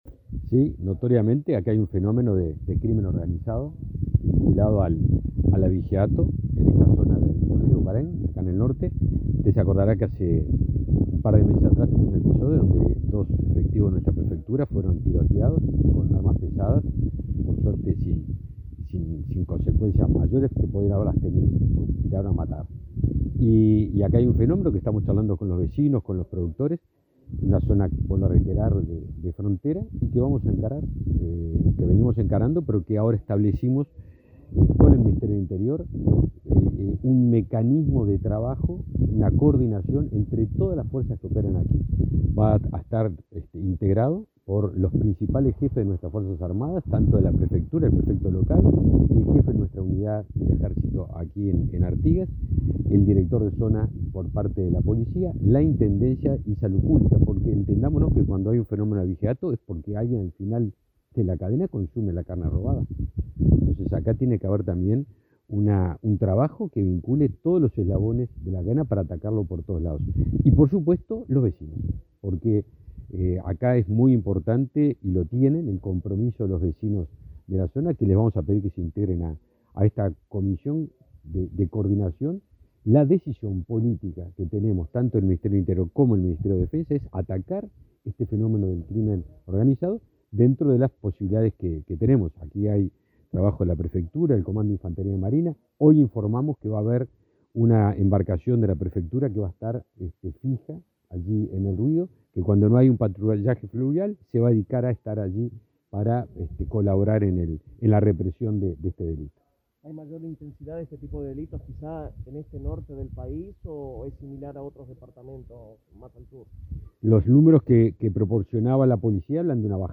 Declaraciones del ministro de Defensa Nacional, Javier García, a la prensa